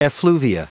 Prononciation du mot effluvia en anglais (fichier audio)
Prononciation du mot : effluvia